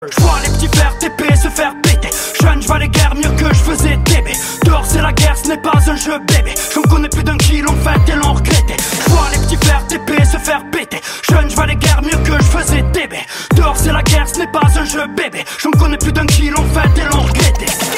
Catégorie: Rap - Hip Hop